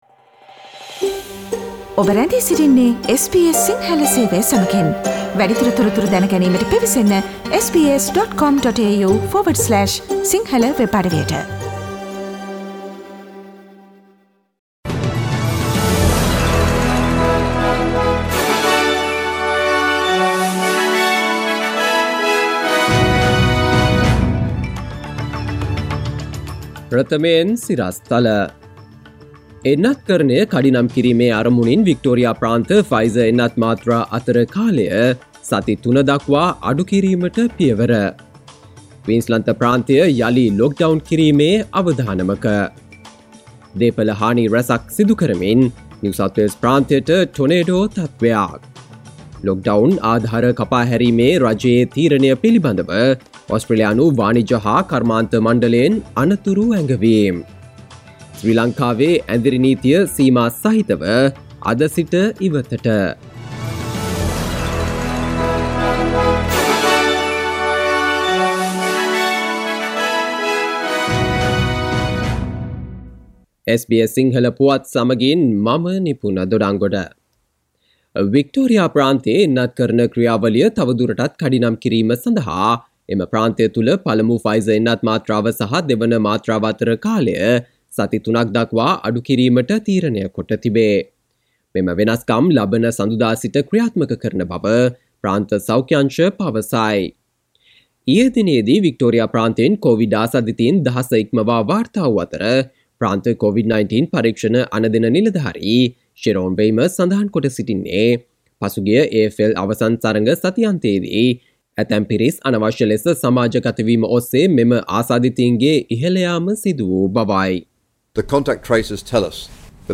සවන්දෙන්න 2021 ඔක්තෝබර් 01 වන සිකුරාදා SBS සිංහල ගුවන්විදුලියේ ප්‍රවෘත්ති ප්‍රකාශයට...